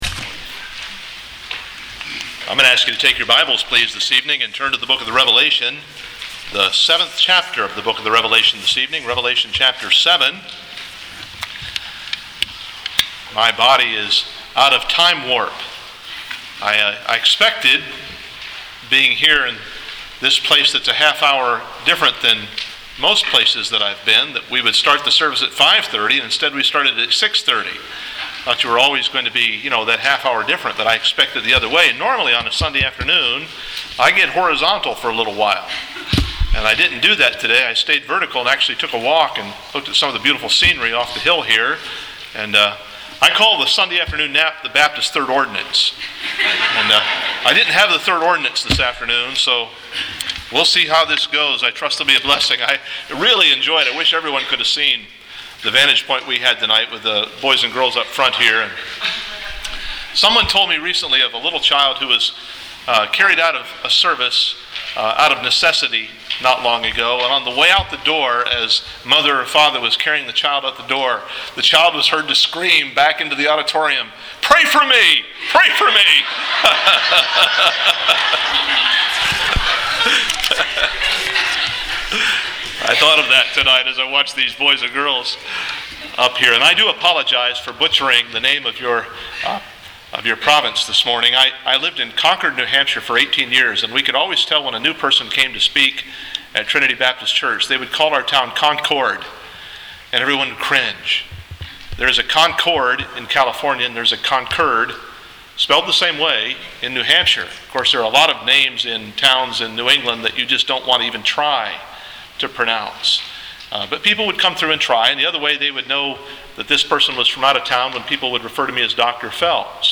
Sermon MP3
PM Service